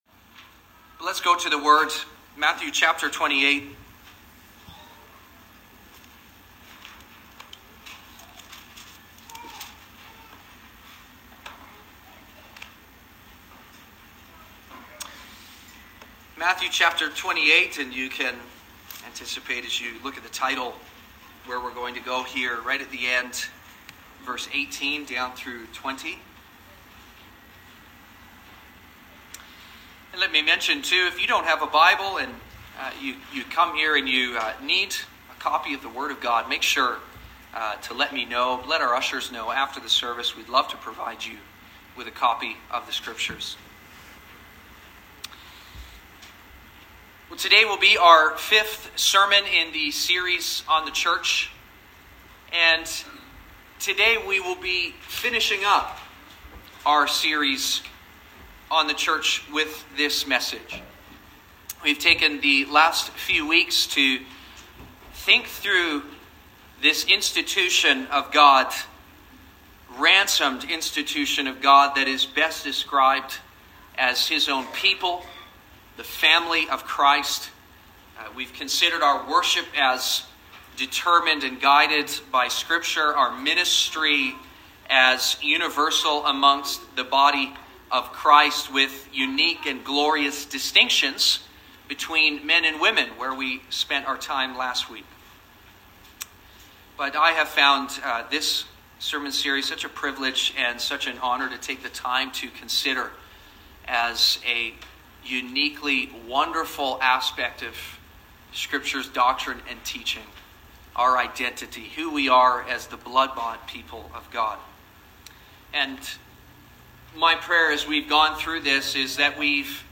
Sermons | Sonrise Community Baptist